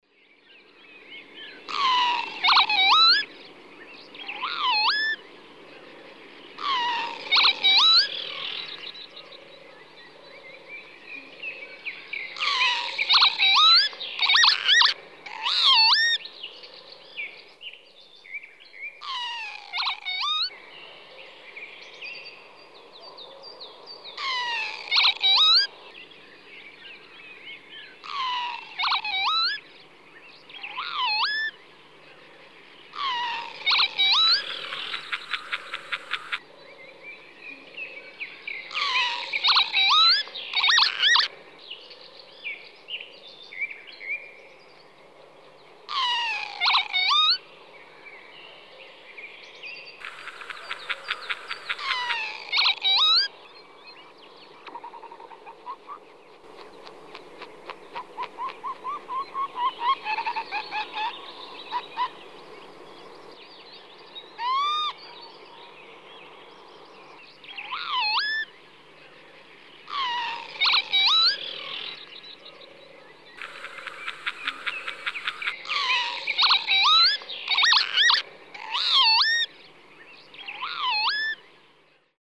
hlas čejky při toku ve formátu MP3.